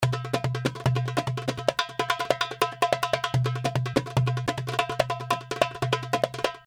145 BPM malfuf and baladi darbuka (32 variations)
The darbuka is playing malfuf and baladi style.
This package contains real darbuka loops in malfuf and baladi beat, playing at 145 bpm.
The darbuka was recorded with vintage neumann u87 in a dry room by a professional Darbuka player. The darbuka you are hearing and downloading is in stereo mode, that means that The darbuka was recorded twice.(beat in the left speaker).
There is only light and perfect analog EQ and light compression, giving you the The opportunity to shape the loops in the sound you like in your song.